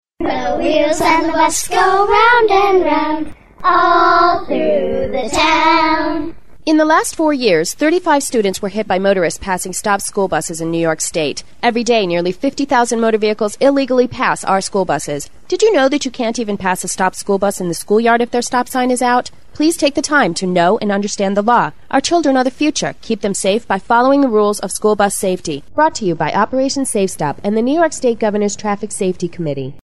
Audio Listen on-line! 30-second Radio ad*.
Children singing in the background - The Wheels on the Bus Go Round and Round All Through the Town.
safe-stop-PSA-wheels-on-bus-song.MP3